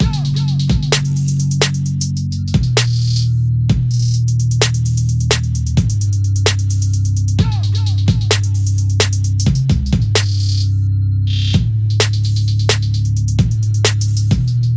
简单的陷阱鼓乐
Tag: 127 bpm Trap Loops Drum Loops 1.27 MB wav Key : Unknown